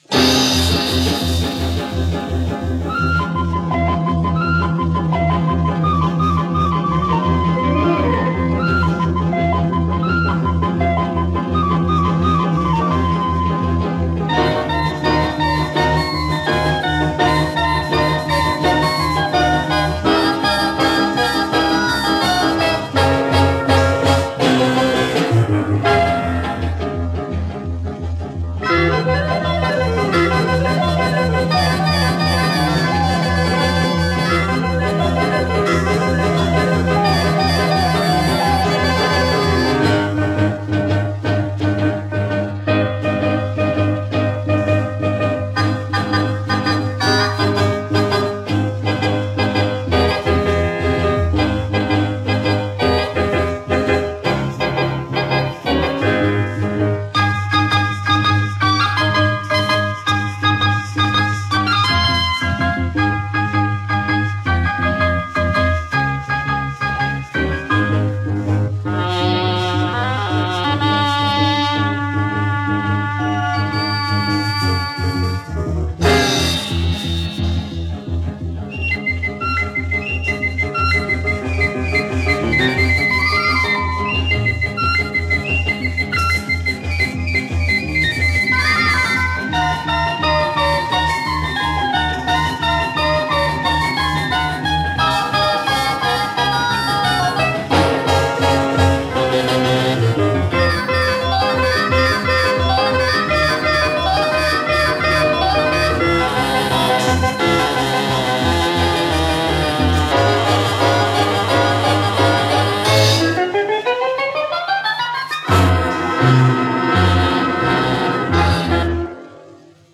Осмелился почистить данные пьесы от пластиночного шума.
Звук стал похож на вещательный.